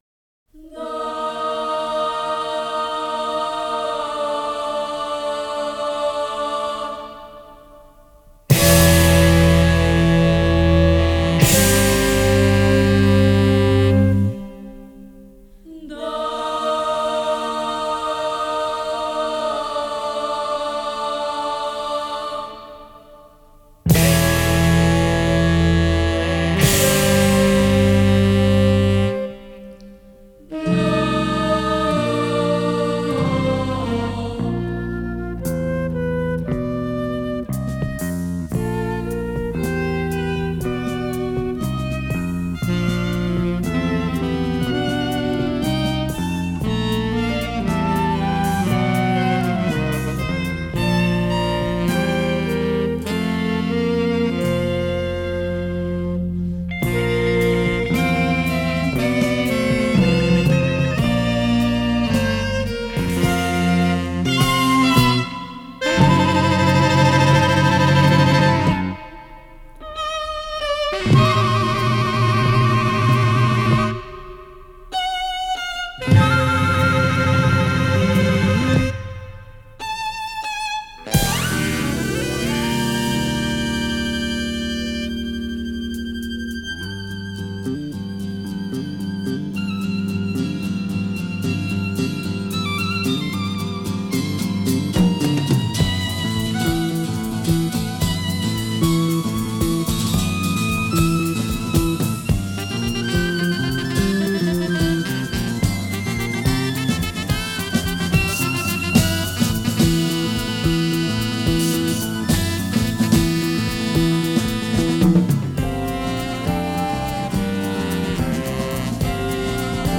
mostly instrumental music
complex, energetic, and diabolical